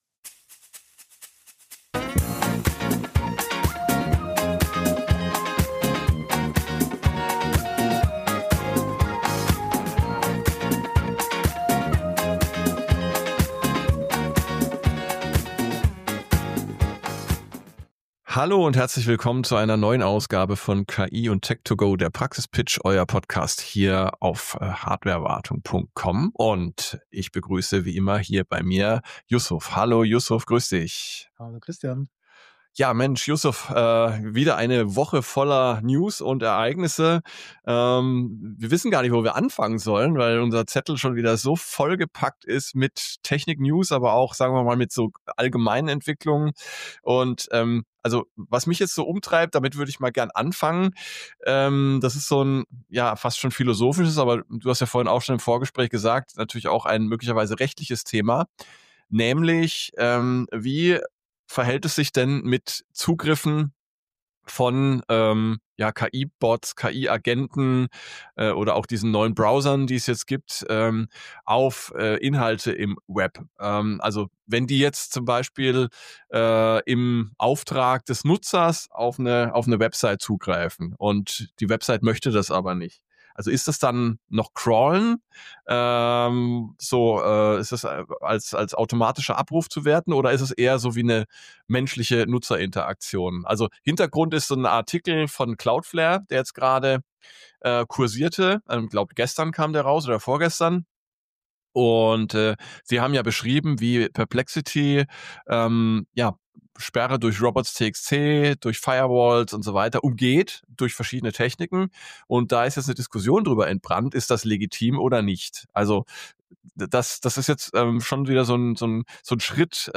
Was bedeutet das für Content-Ersteller, für Unternehmen, für unsere Gesellschaft – und für dich persönlich? Ein ehrliches, tiefgehendes Gespräch über technische Durchbrüche, regulatorische Grauzonen, die Angst vor Veränderung und warum Optimismus trotzdem (oder gerade deshalb) die beste Strategie ist.